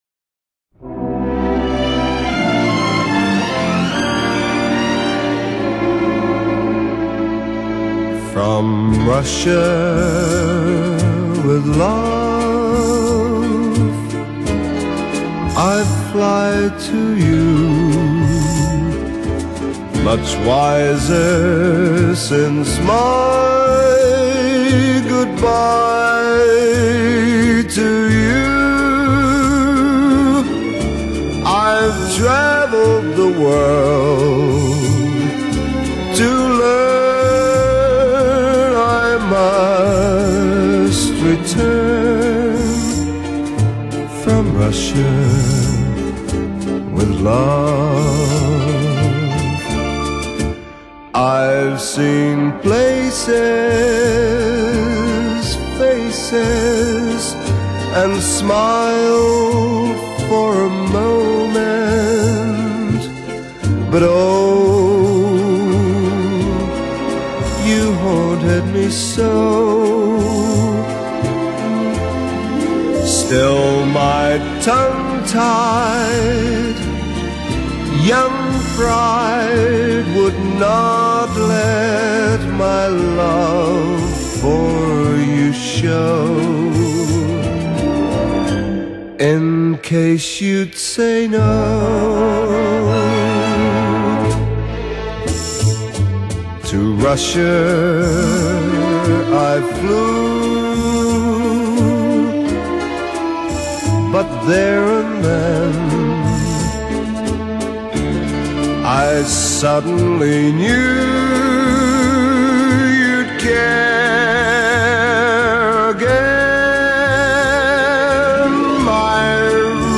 Genre: Soundtrack / Pop